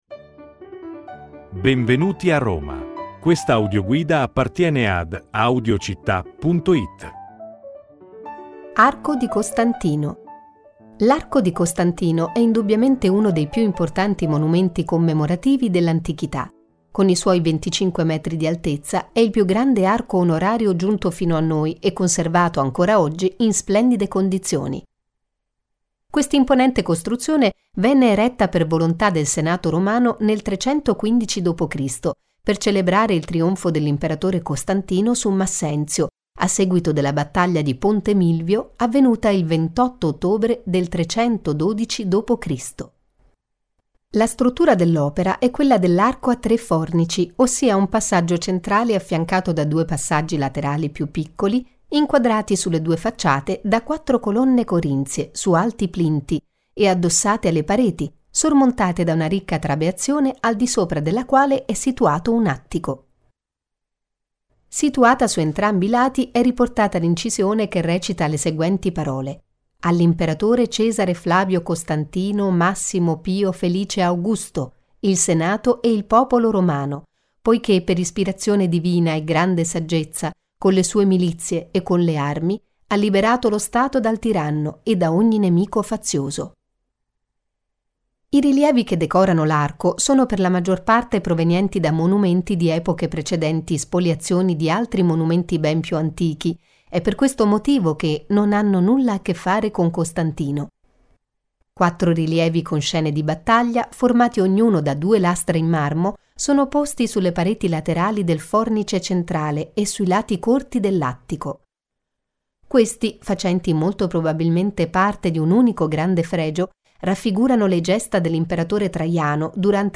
Audioguida Roma – Arco di Costantino